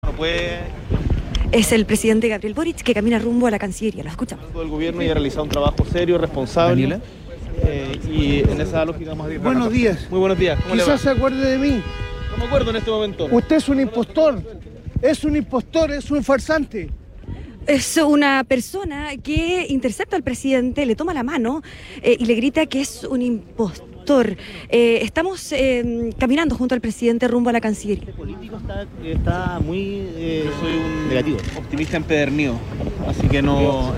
Durante esta jornada de miércoles, el presidente Gabriel Boric fue increpado por una persona en las cercanías de La Moneda.
El hecho ocurrió mientras el mandatario conversaba con los medios de comunicación sobre contingencia política, como el proceso constitucional y la Ley de Presupuestos.
Cabe mencionar que, en la instancia, el Presidente se dirigía caminando -por calle Teatinos- hasta la Conferencia anual del foro Global de la OCDE sobre productividad.